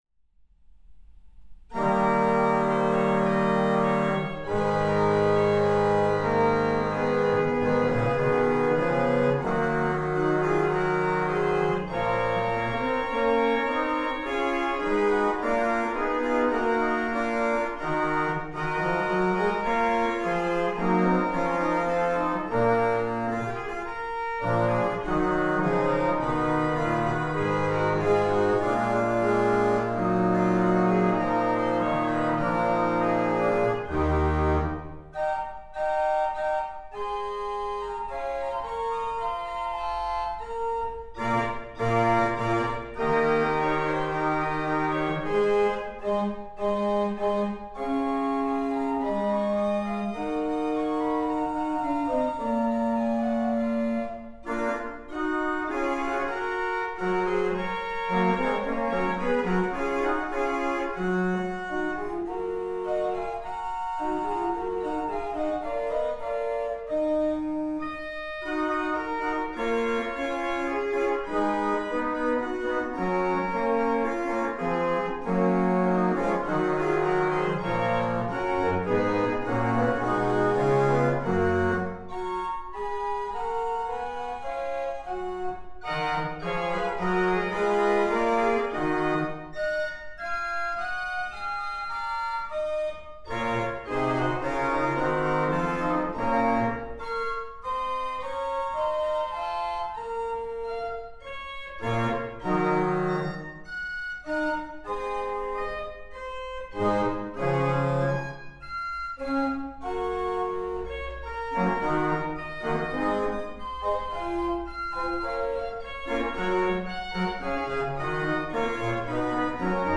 Im Zeitraffer durch sieben Jahrhunderte Orgelklang
Orgel in St. Martin (Tellingstedt)
Ein Großteil der Pfeifen dieser ältesten, spielbaren Orgel Schleswig-Holsteins stammt noch aus dem 1642 von Tobias Brunner aus Lunden gebauten Instrument.